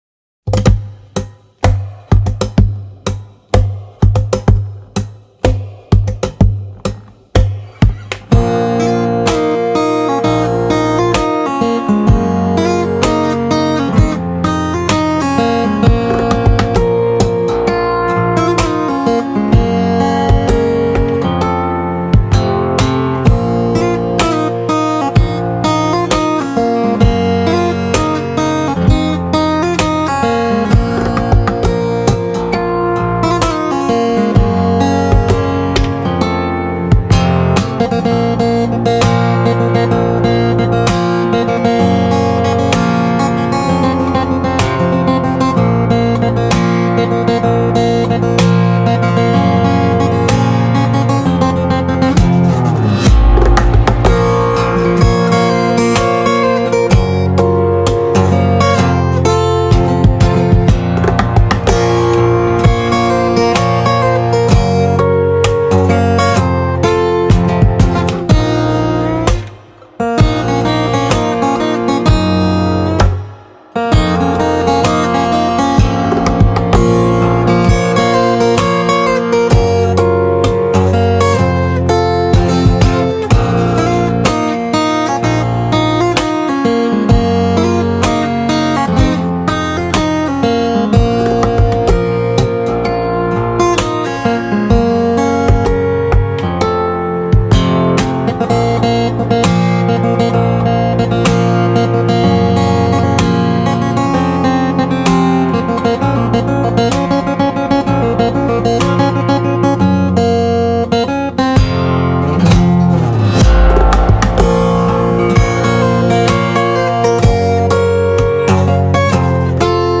جلوه های صوتی
دانلود صدای گیتار 1 از ساعد نیوز با لینک مستقیم و کیفیت بالا